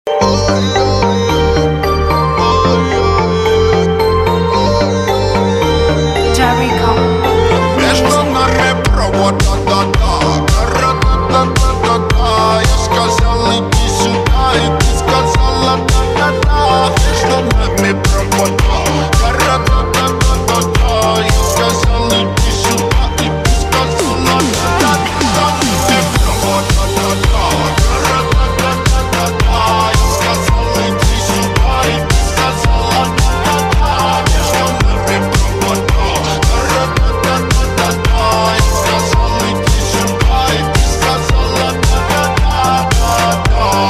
hip-hop/rap track